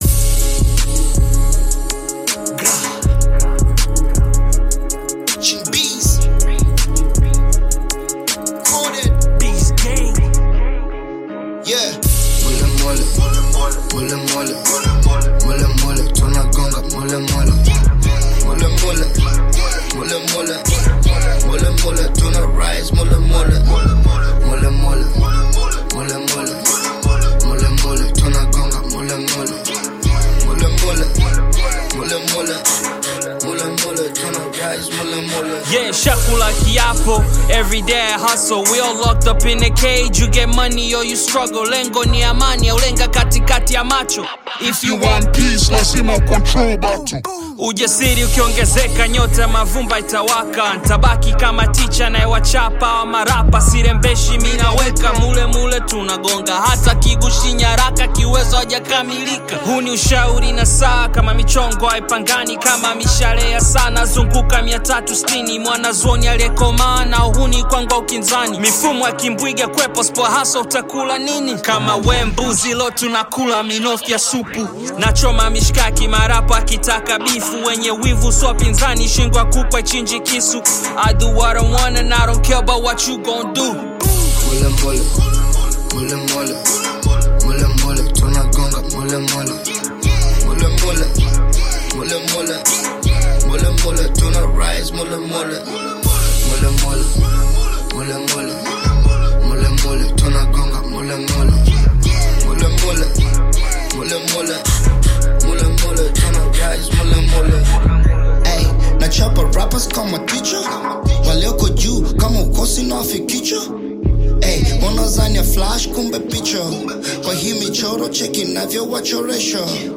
This one is special for Hip-Hop Rap lover.
Bongo Flava